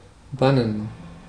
Ääntäminen
IPA: /ˈbanən/